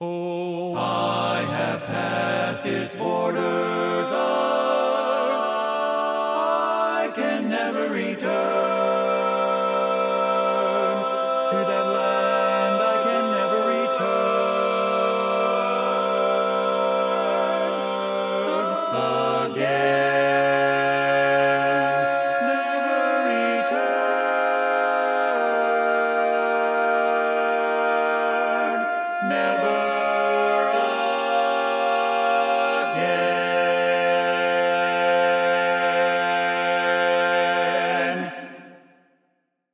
Key written in: F Major
Type: Barbershop